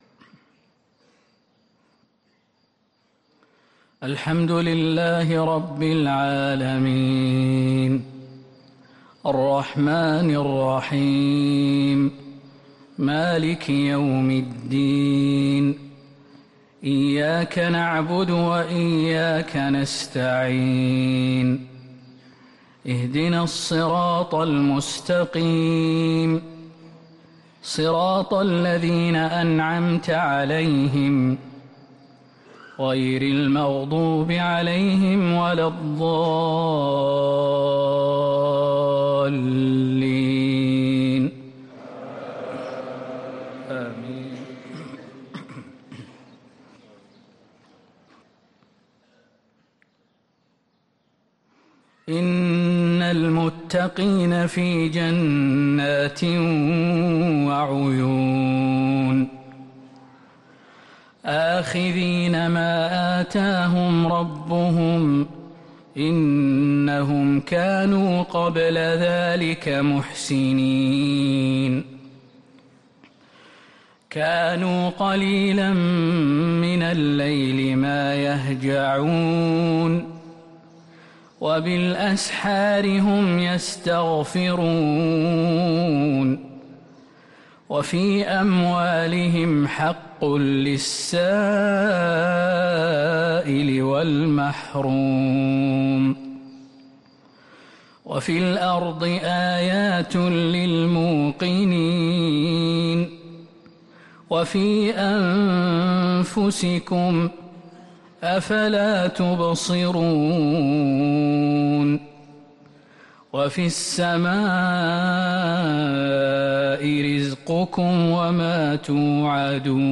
فجر الأربعاء 9-8-1444هـ من سورة الذاريات | Fajr prayer from Surah ad-Dhariyat 1-3-2023 > 1444 🕌 > الفروض - تلاوات الحرمين